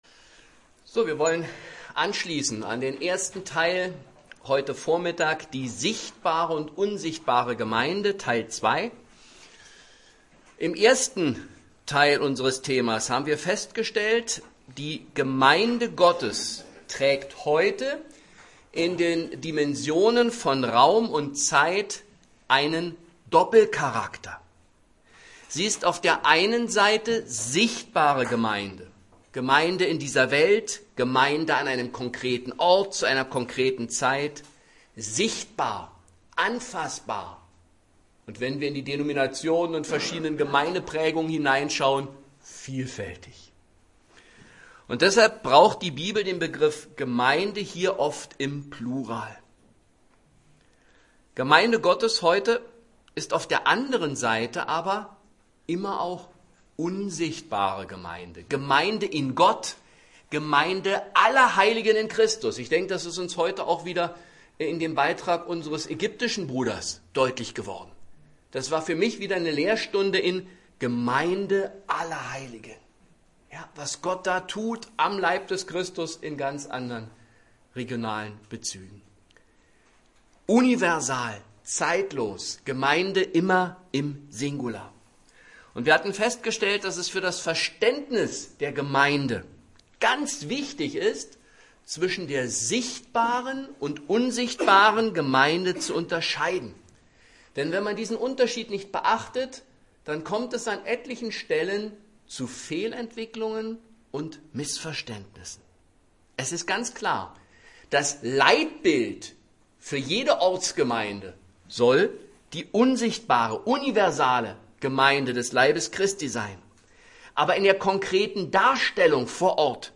Dienstart: Andere Vorträge